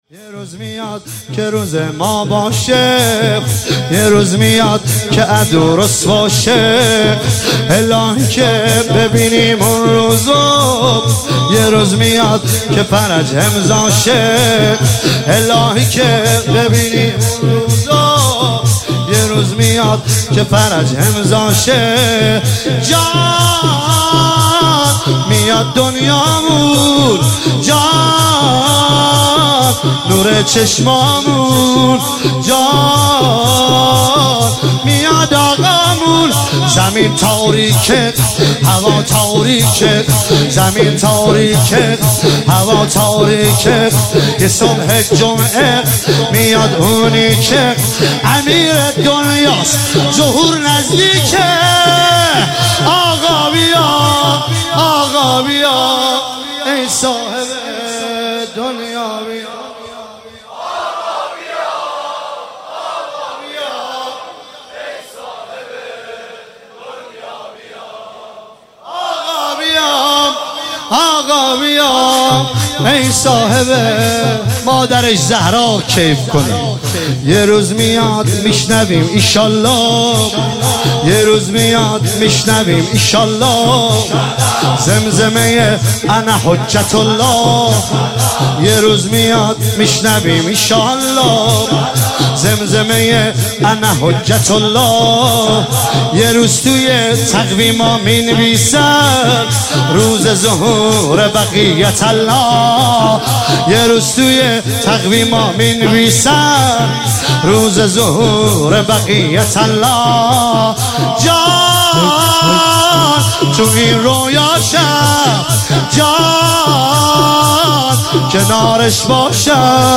عنوان شب بیست و سوم ماه مبارک رمضان ۱۳۹۸
شور یه روز میاد که روز ما باشه